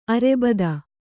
♪ arebada